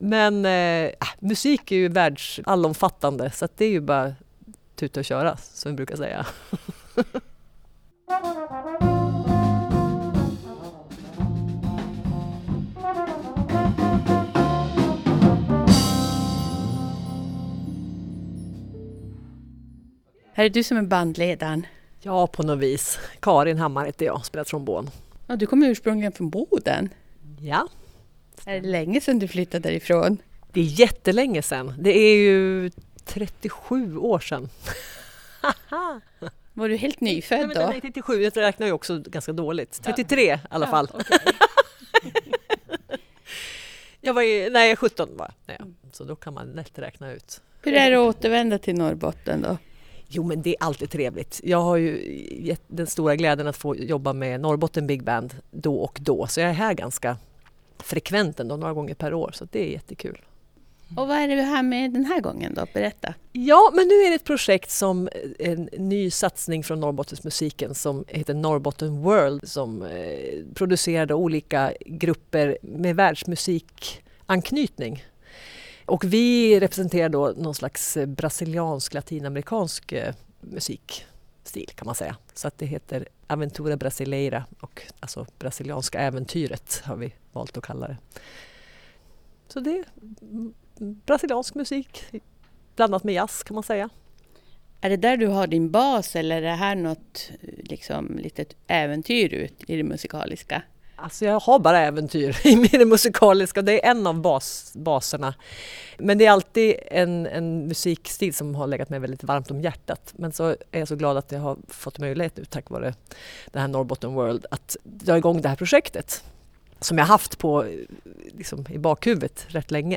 Reportage om Aventura Brasileira
I repetitionsrummet på Kulturens hus i Luleå i slutet av februari är det svårt att sitta still som åhörare. Det svänger av samba och bossanova i ett jazzigt uttryck. Fem musiker är sammanförda för att bygga soundet tillsammans.